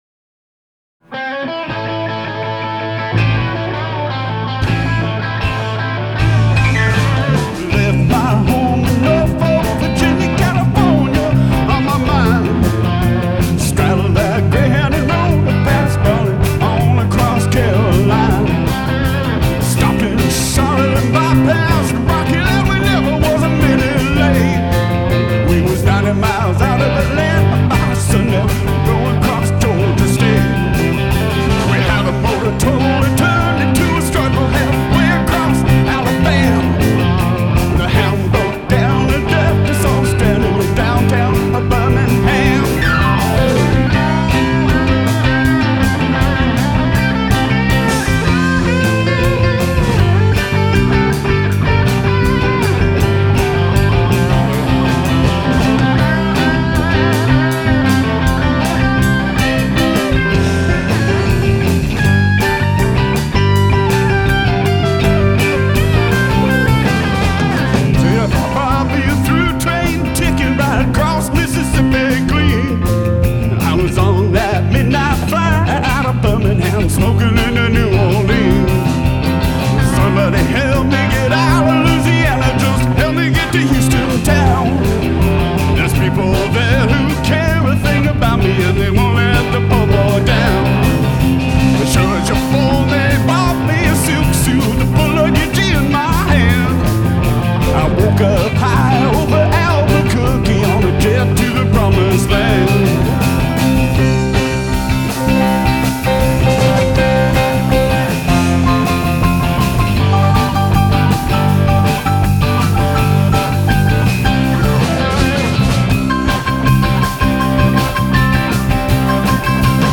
Seattle Peace Concert (Seattle) - 7/11/10